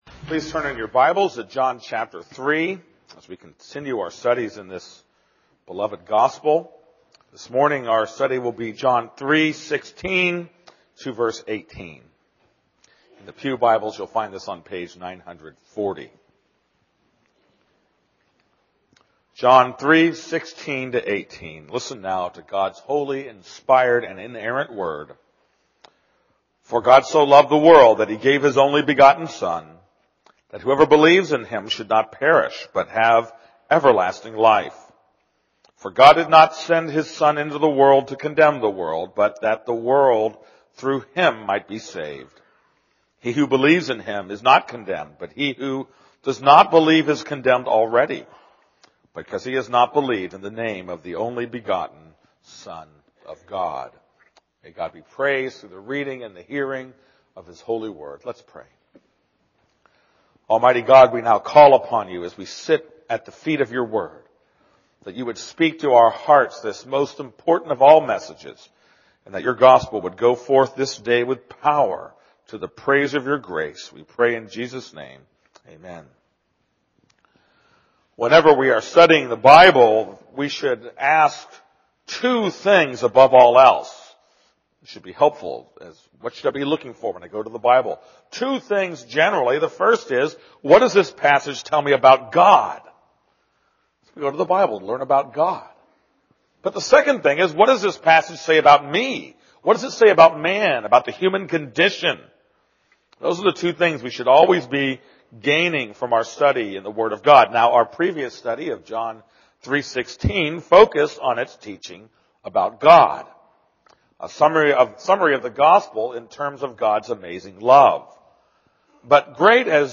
This is a sermon on John 3:16-18.